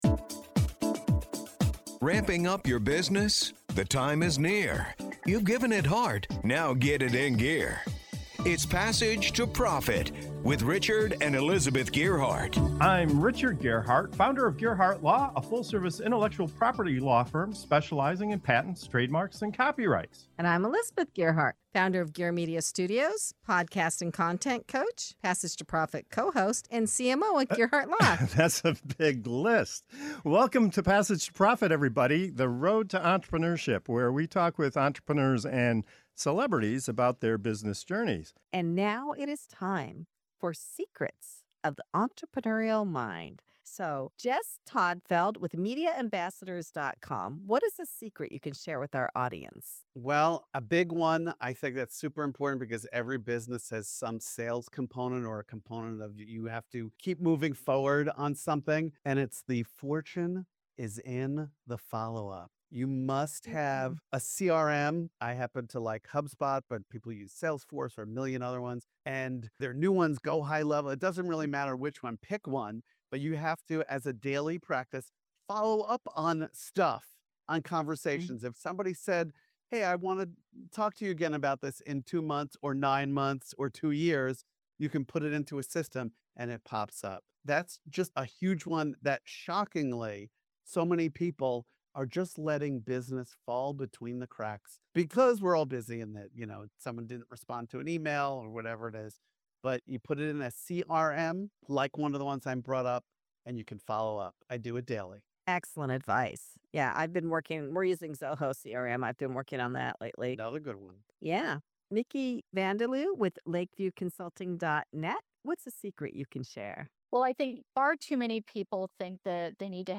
In this segment of "Secrets of the Entrepreneurial Mind", our panel of entrepreneurs pulls back the curtain on the real secrets behind business success—from the power of consistent follow-up and choosing the right CRM, to embracing an abundance mindset and launching your big idea before you quit your day job.